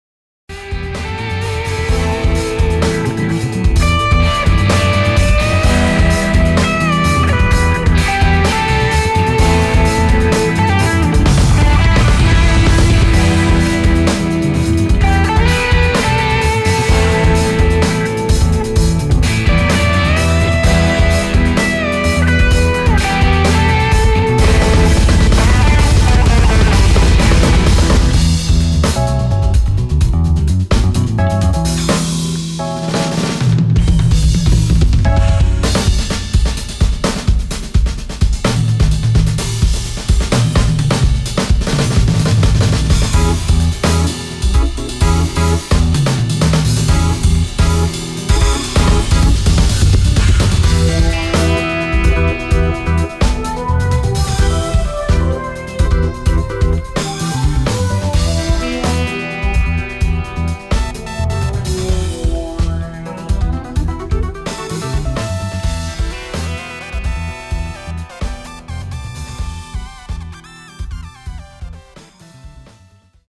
Meine Vermutung ist kein Drumkit/eDrums. Auf mich wirken die Hats statisch, die Fills seltsam.